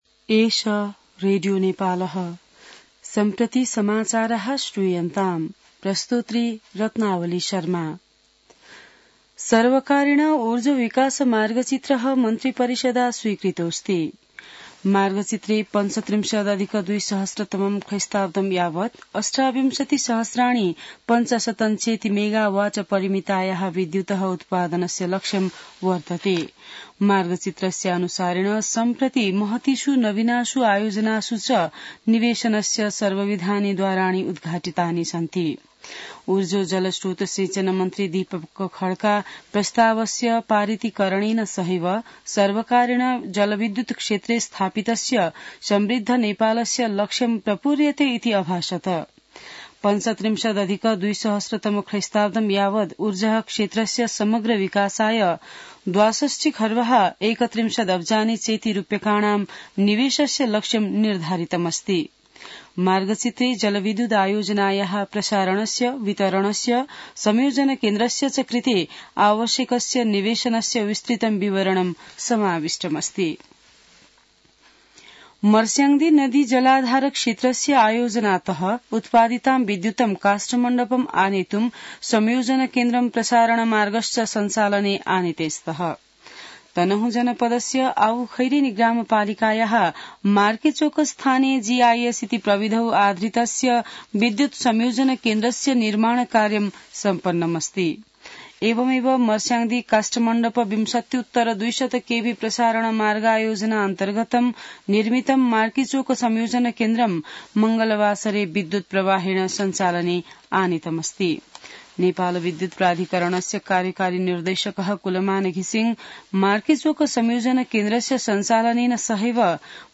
An online outlet of Nepal's national radio broadcaster
संस्कृत समाचार : १९ पुष , २०८१